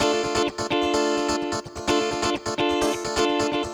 VEH3 Electric Guitar Kit 1 128BPM
VEH3 Electric Guitar Kit 1 - 12 D min.wav